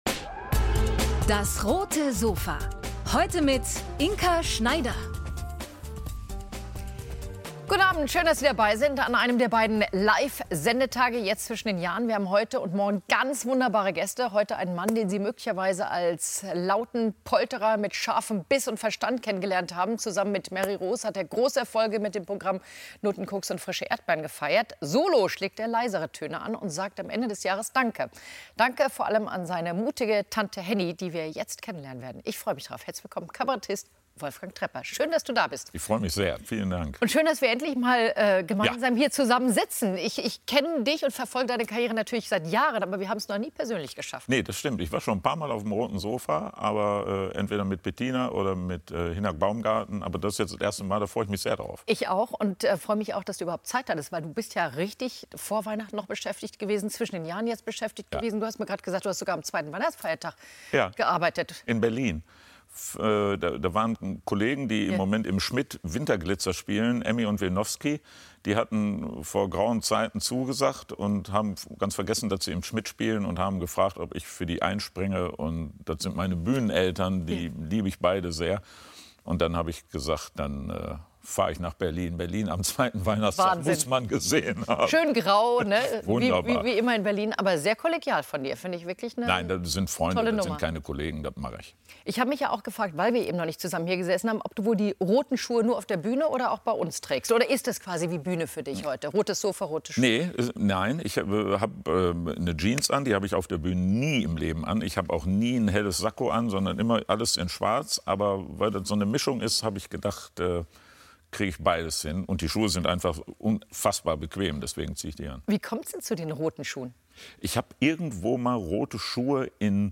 Kabarettist und Autor Wolfgang Trepper auf dem Roten Sofa ~ DAS! - täglich ein Interview Podcast